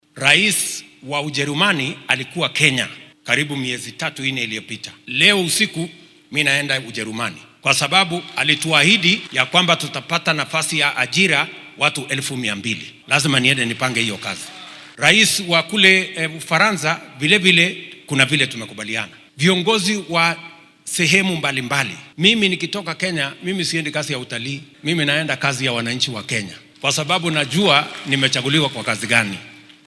Hoggaamiyaha dalka oo ka hadlaya waxyaabaha uga qorsheysan Jarmalka iyo Faransiiska ayaa yiri.